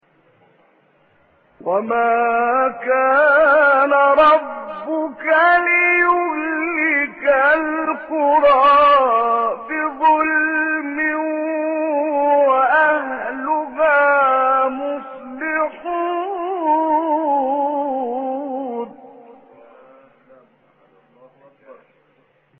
گروه شبکه اجتماعی: نغمات صوتی از قاریان ممتاز مصری ارائه می‌شود.
مقطعی از عبدالعزیز حصان/ مقام رست